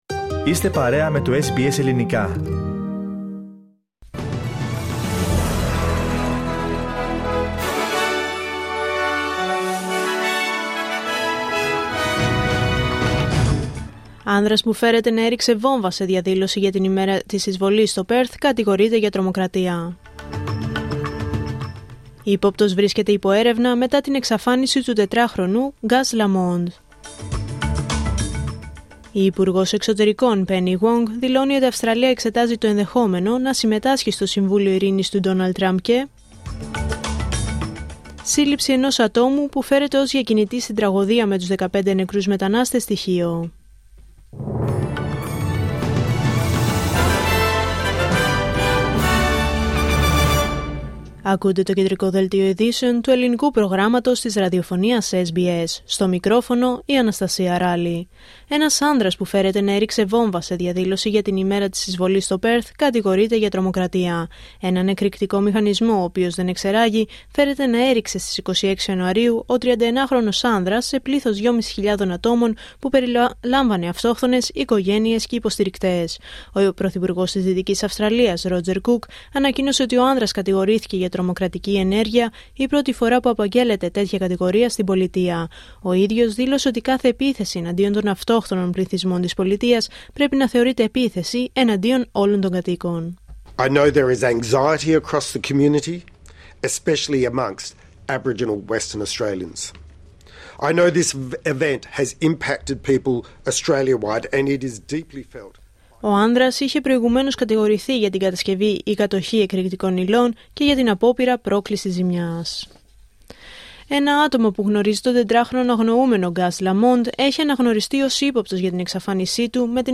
Δελτίο Ειδήσεων Πέμπτη 5 Φεβρουαρίου 2026